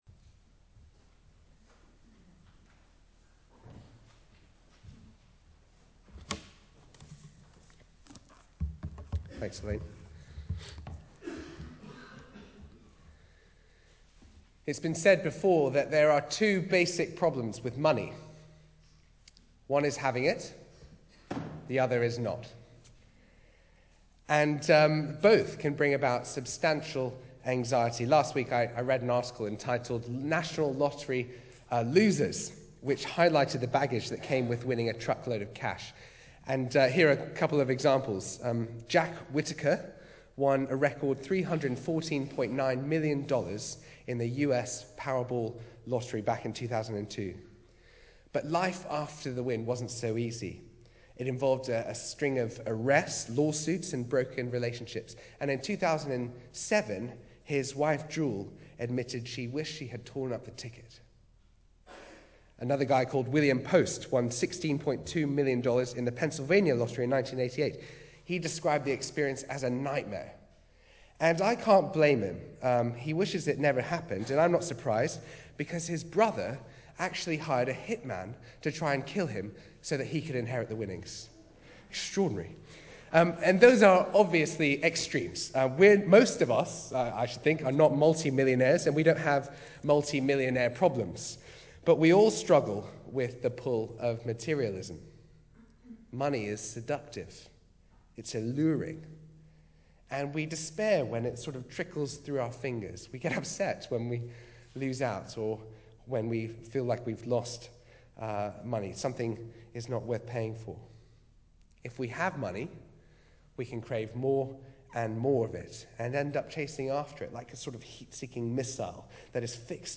Media for Arborfield Morning Service on Sun 05th May 2013 10:00
Theme: The gift of money Sermon